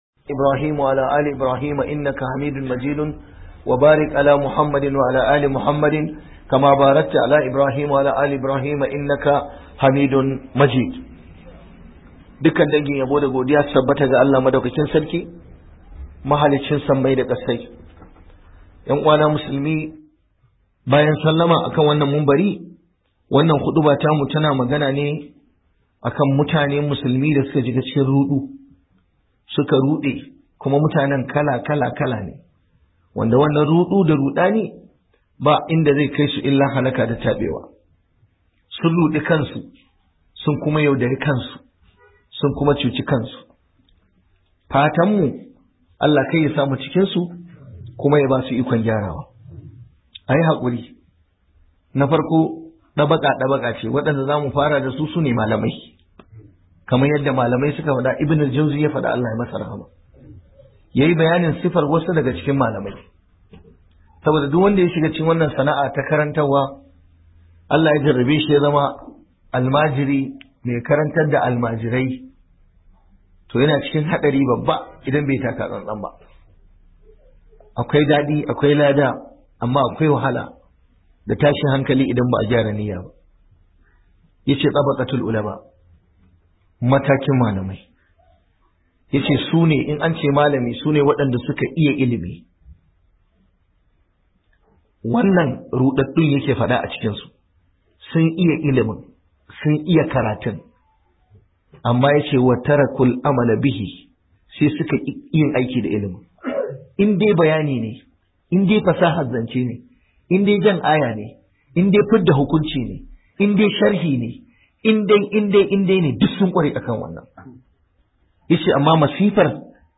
Ruɗun da Musulmai suka shiga - Huduba